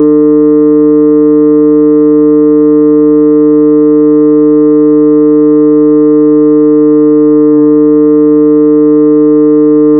(small) faint pulsing tone
small-faint-pulsing-tone-p235jp6h.wav